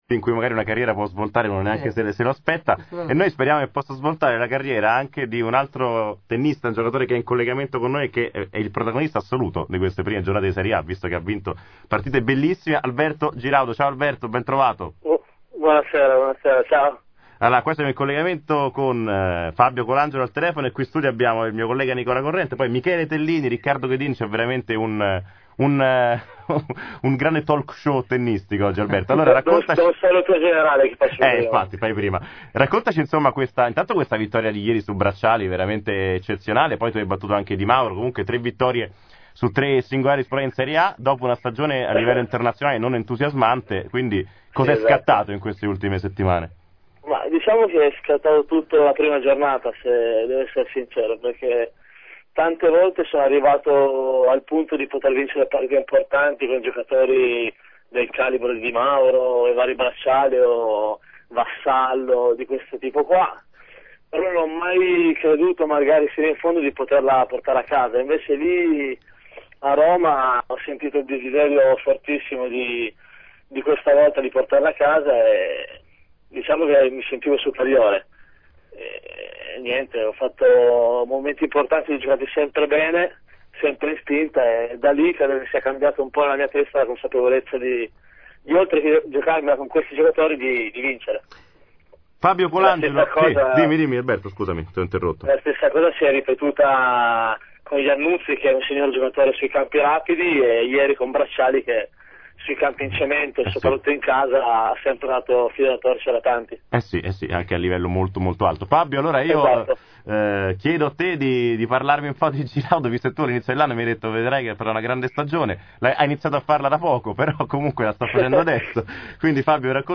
Qui di seguito potete risentire la replica della puntata di ieri sera di Spazio Tennis, andata in onda come sempre su Nuova Spazio Radio.